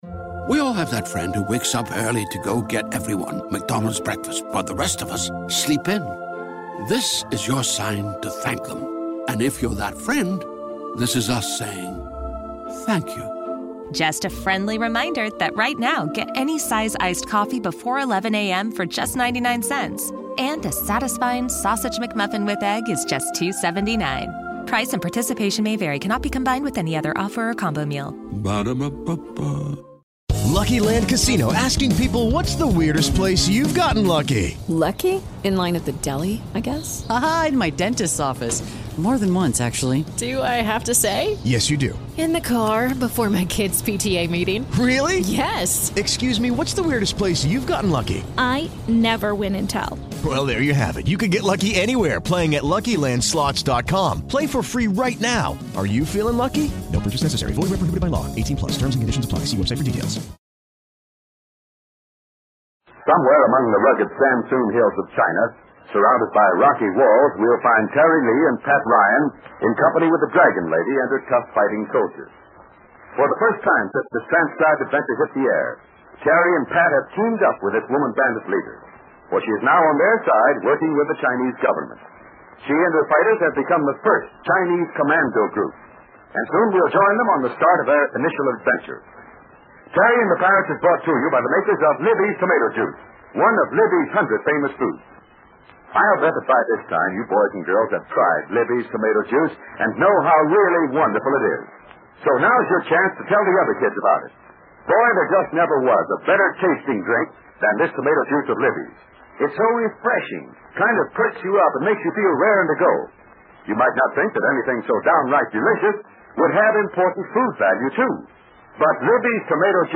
Terry and the Pirates was an American radio serial adapted from the comic strip of the same name created in 1934 by Milton Caniff. With storylines of action, high adventure and foreign intrigue, the popular radio series enthralled listeners from 1937 through 1948.